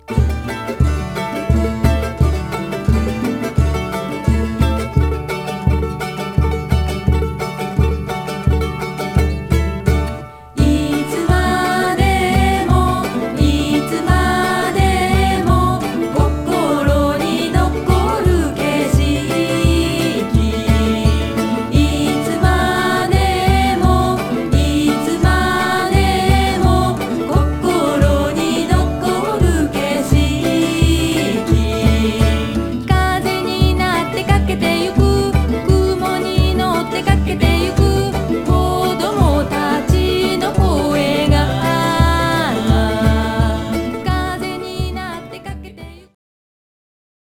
（インストゥルメンタル：ボリビア伝承曲）